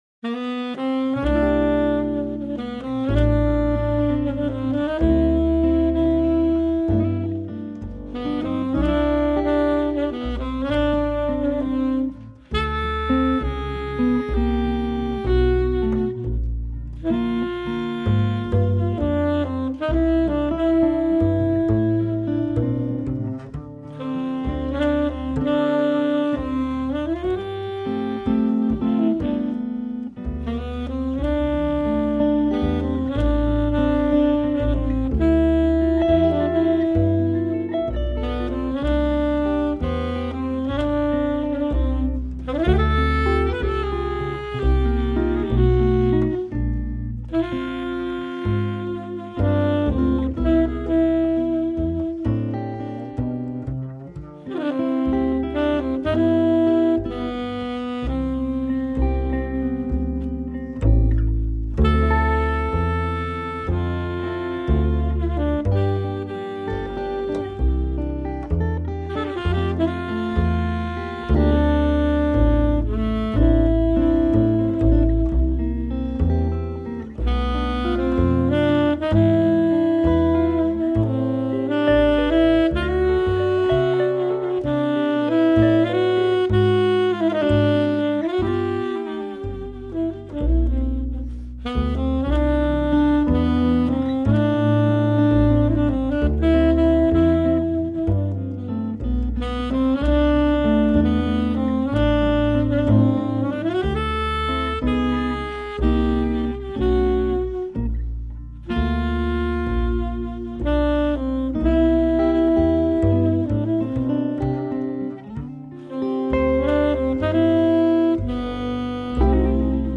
a ballad
tenor sax